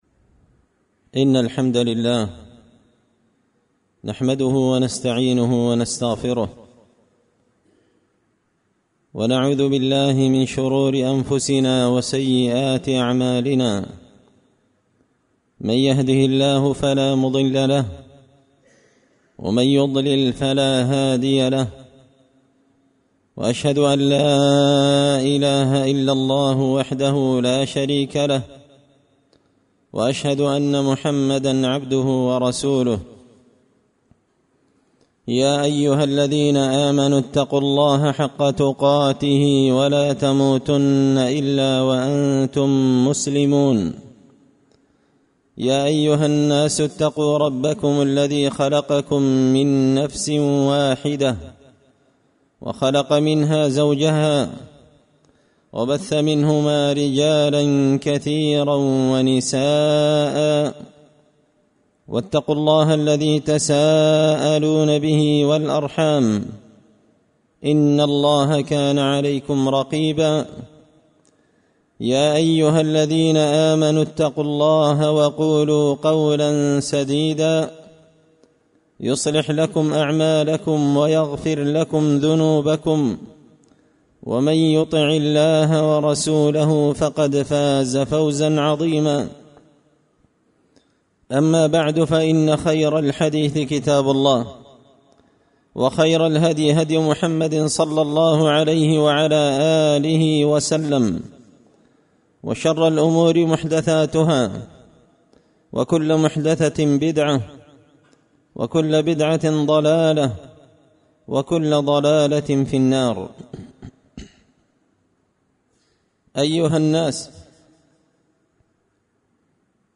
خطبة جمعة بعنوان – أيبتغون عندهم العزة
دار الحديث بمسجد الفرقان ـ قشن ـ المهرة ـ اليمن